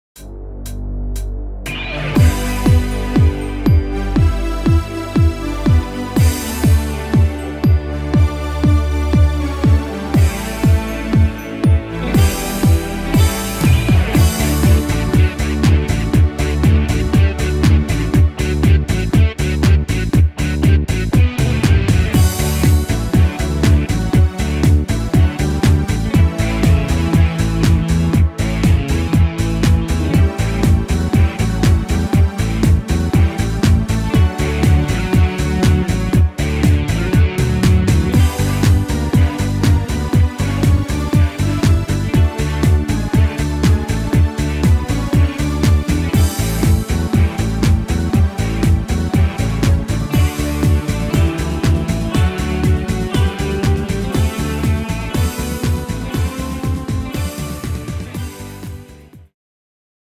Tempo: 120 / Tonart: D-Dur